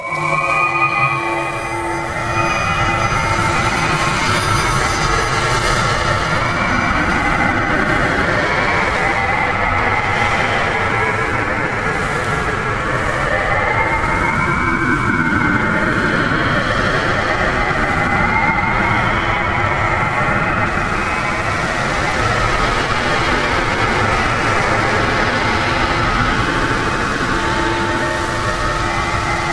恐怖的噪音
Tag: 130 bpm Trap Loops Fx Loops 4.97 MB wav Key : D FL Studio